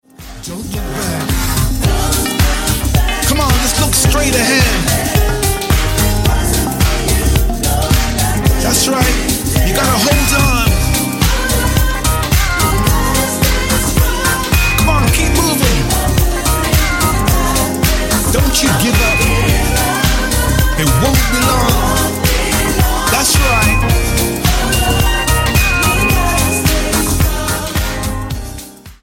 STYLE: Gospel